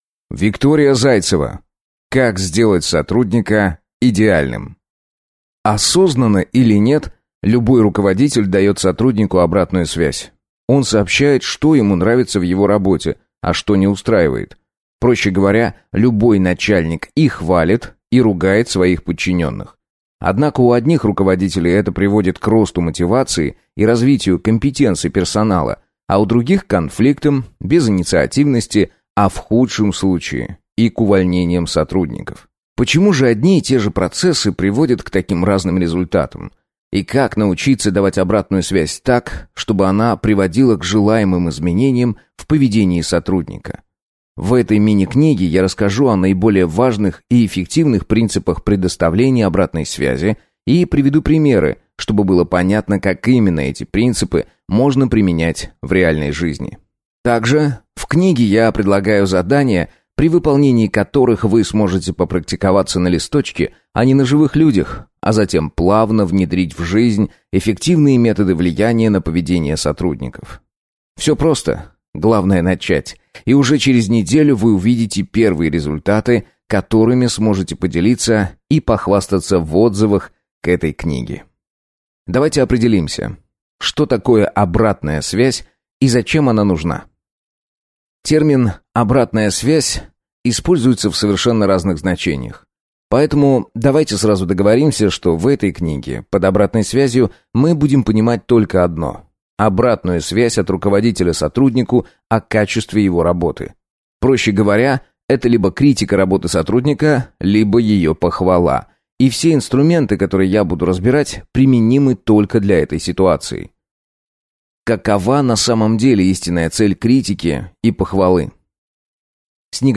Аудиокнига Как сделать сотрудника идеальным | Библиотека аудиокниг